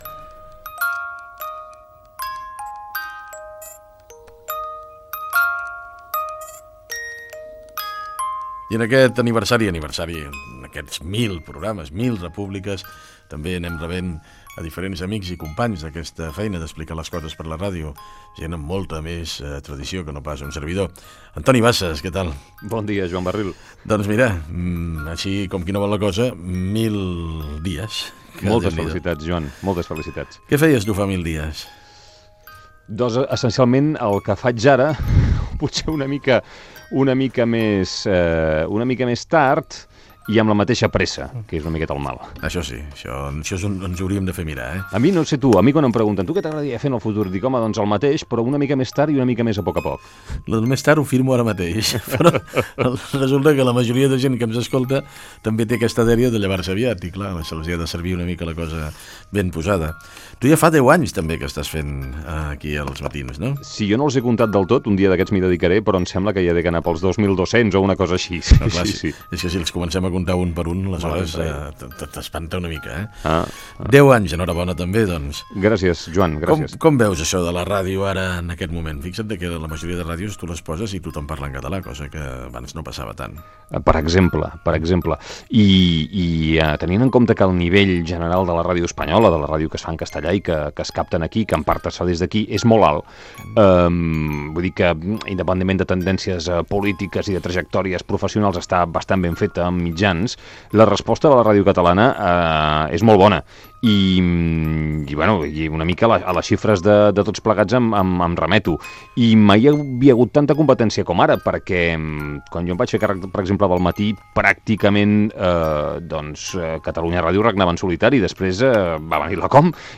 Programa 1000. Fragment d'una entrevista al periodista Antoni Bassas.
Info-entreteniment